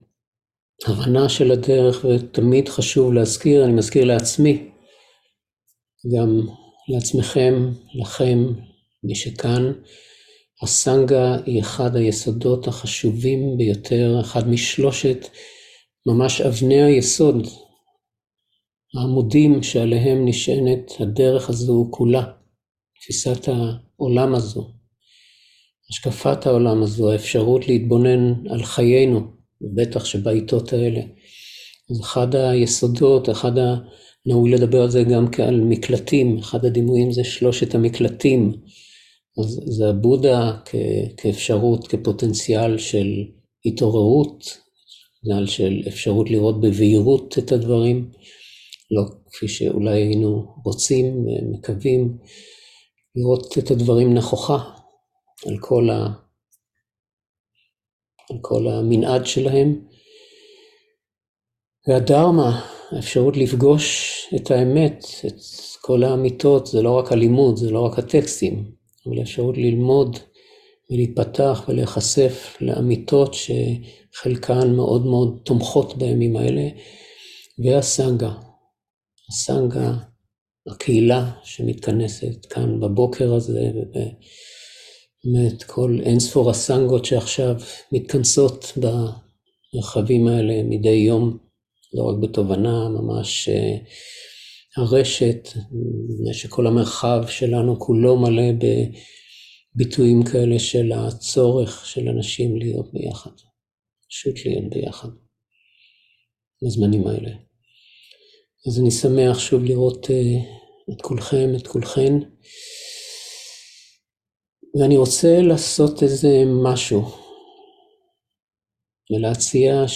30.10.2023 - מרחב בטוח - אפשרות הבחירה שיש לנו בכל רגע (חלק א) - מדיטציה
שם הריטריט: מרחב בטוח